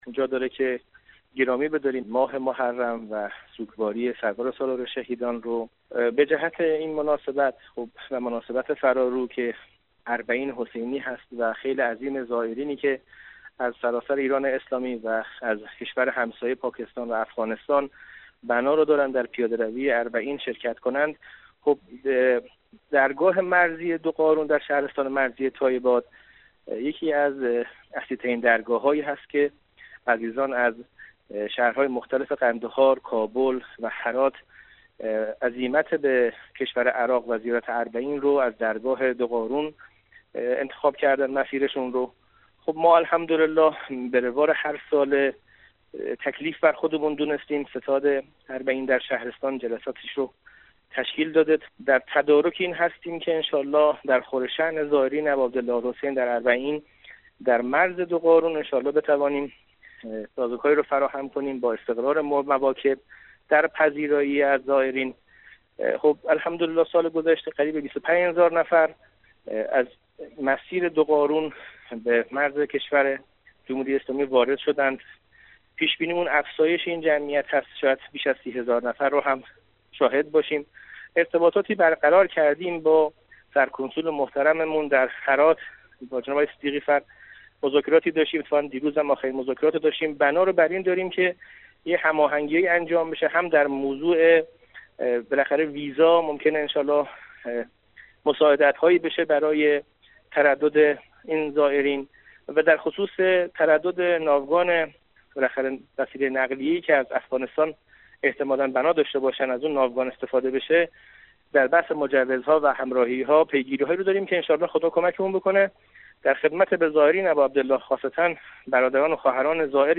مهدی شیردل فرماندار شهرستان مرزی تایباد استان خراسان رضوی ایران در مصاحبه با رادیو دری گفت: در این شهرستان برنامه ریزی ها برای استقبال از زائران اربعین که از افغانستان به کربلا عزیمت می کنند، آغاز شده است.